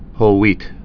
(hōlwēt, -hwēt)